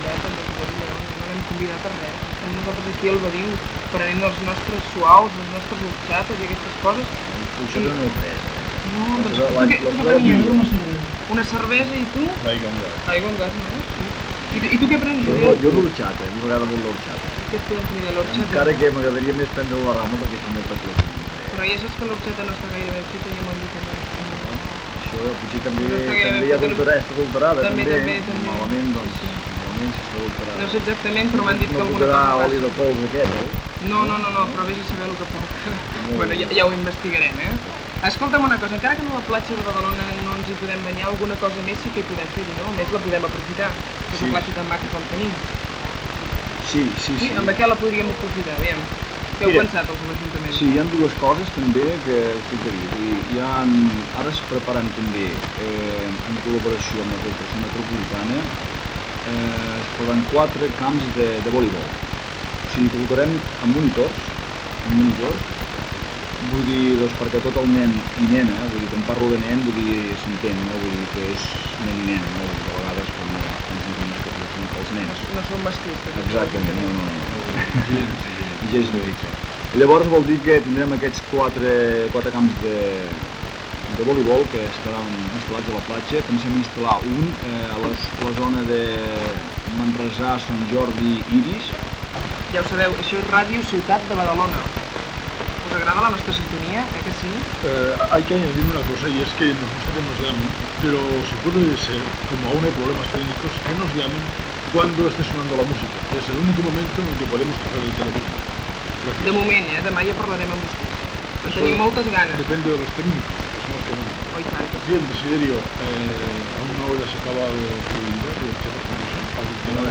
Entreteniment
Qualitat de l'àudio defectuosa.